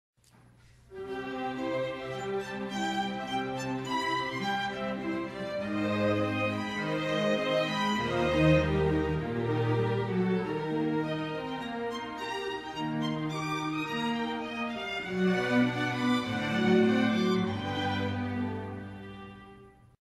• Simfoniskā pasaka "Pēterītis un vilks" (SR) Saklausa mūzikas instrumentiem atbilstošus tēlus